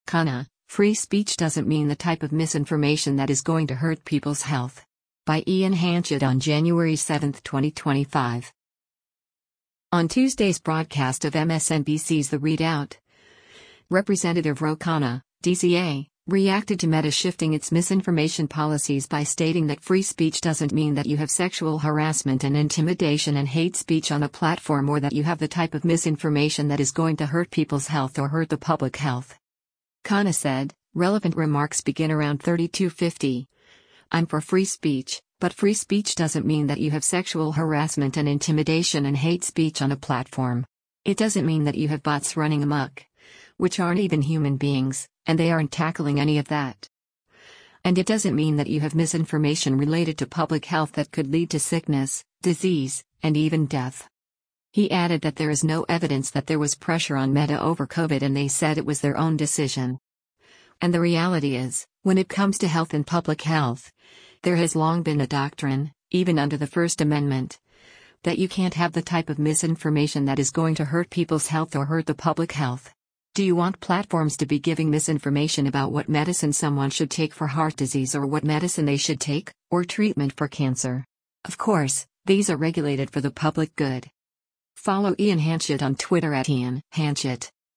On Tuesday’s broadcast of MSNBC’s “The ReidOut,” Rep. Ro Khanna (D-CA) reacted to Meta shifting its misinformation policies by stating that “free speech doesn’t mean that you have sexual harassment and intimidation and hate speech on a platform” or that you “have the type of misinformation that is going to hurt people’s health or hurt the public health.”